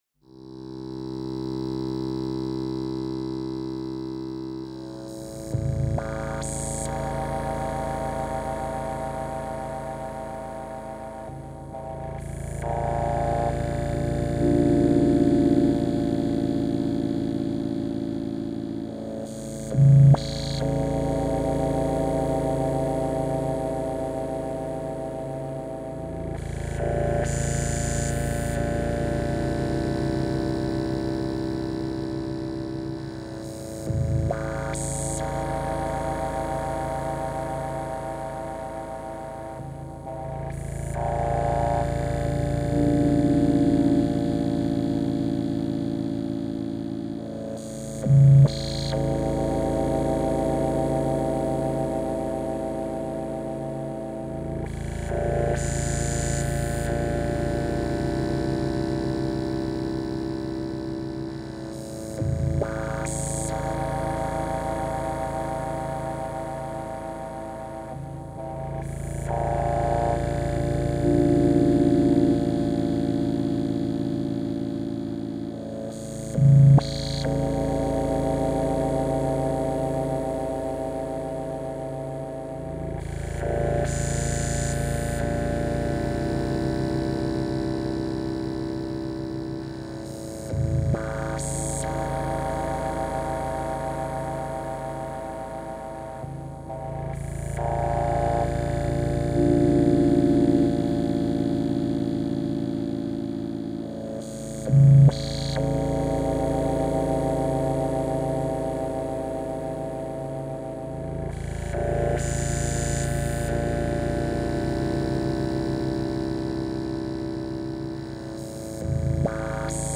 File under: Avantgarde
introduces more rhythmic layers.